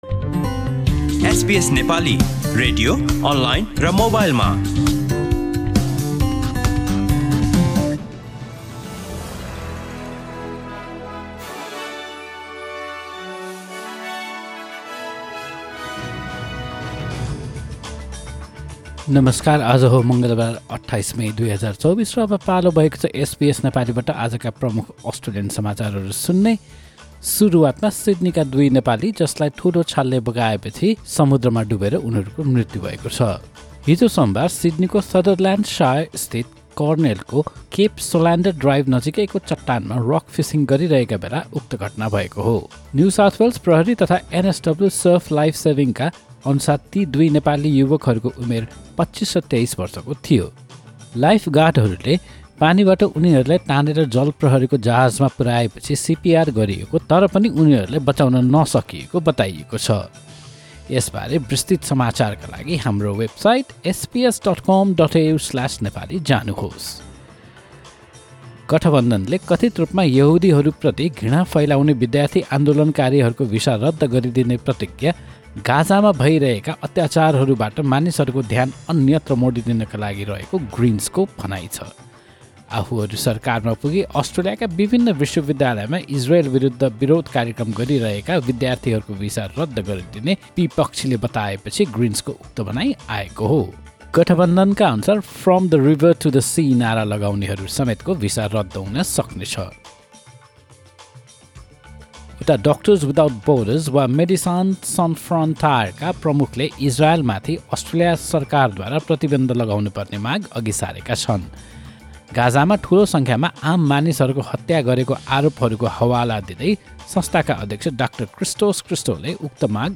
एसबीएस नेपाली प्रमुख अस्ट्रेलियन समाचार: मङ्गलवार, २८ मे २०२४